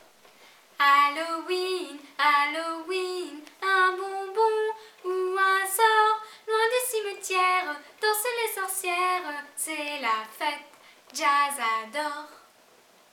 DSC_0089-Chanson-3-FRA.mp3